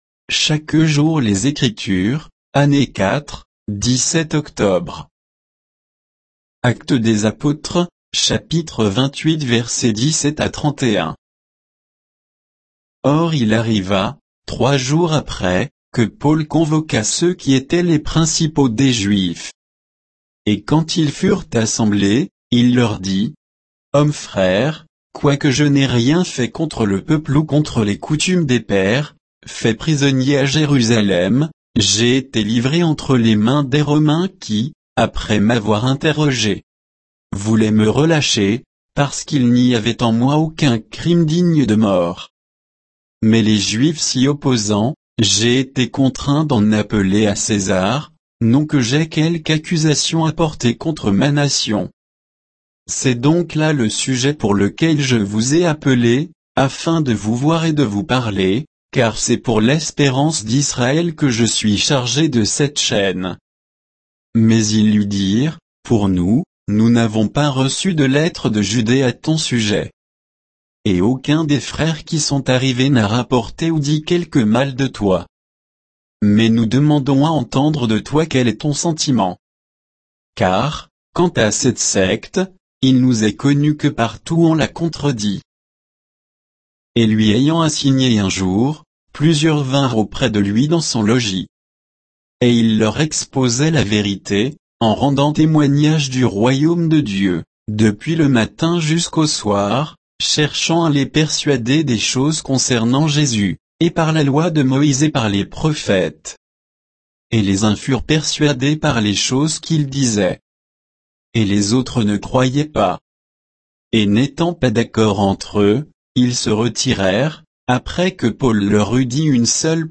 Méditation quoditienne de Chaque jour les Écritures sur Actes 28, 17 à 31